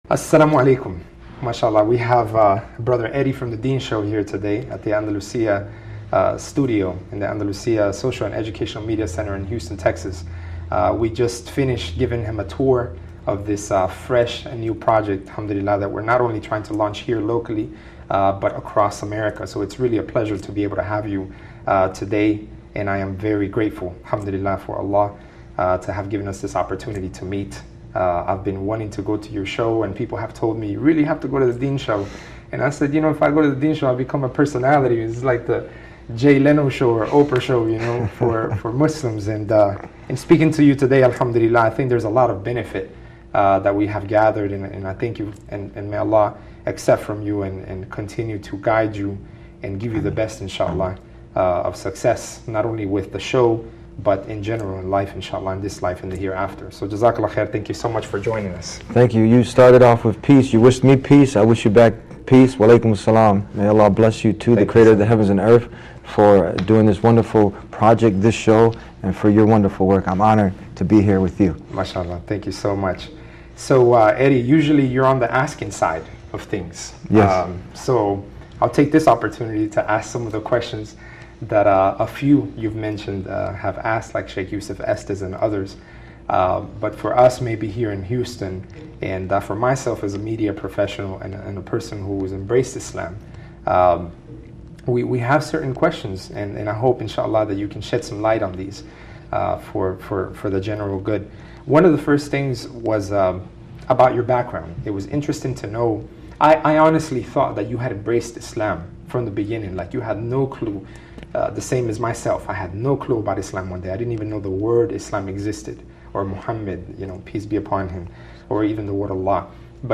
The Deen Show” Exclusive in-depth interview